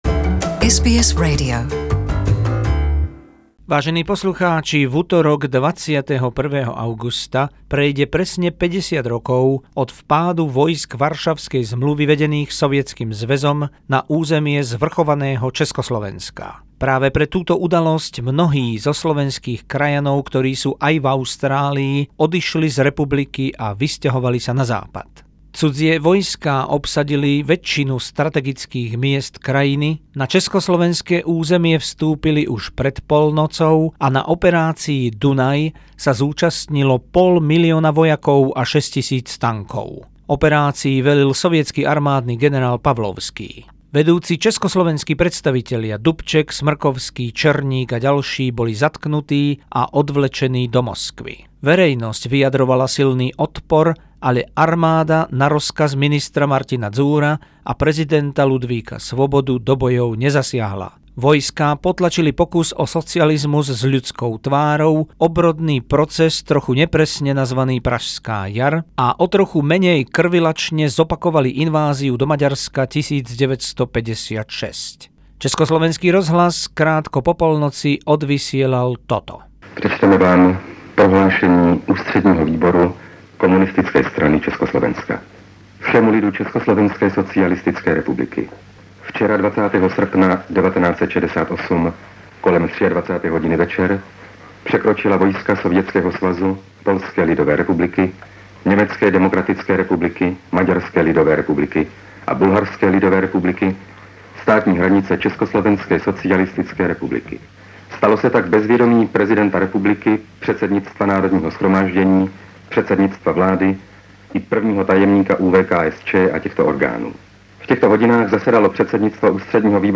Mnohí mladí dnes nerozumejú, o čo išlo. Črta obsahuje aj telefonát